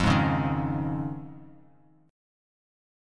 Fm7#5 chord